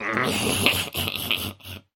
Звуки гоблина
Здесь вы найдете рычание, скрежет, зловещий смех и другие устрашающие эффекты в высоком качестве.
Хохот гоблина